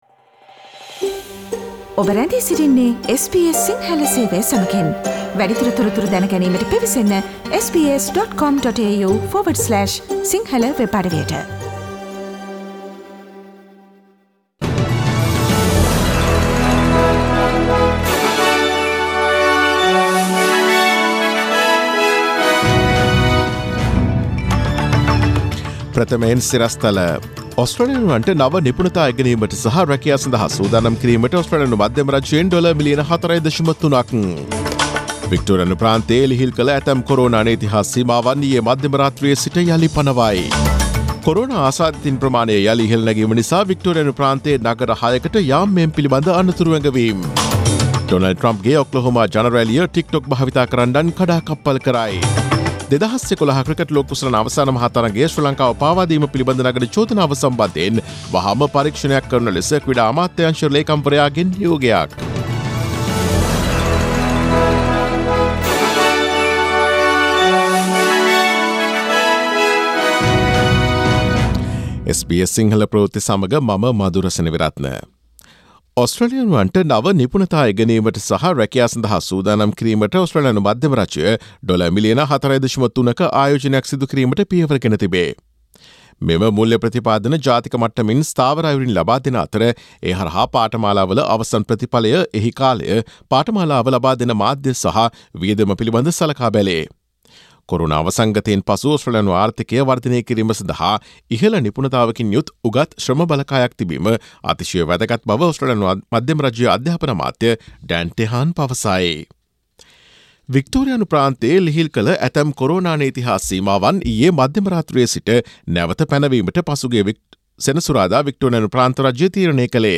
Daily News bulletin of SBS Sinhala Service: Monday 22 June 2020
Today’s news bulletin of SBS Sinhala Radio – Monday 22 June 2020 Listen to SBS Sinhala Radio on Monday, Tuesday, Thursday and Friday between 11 am to 12 noon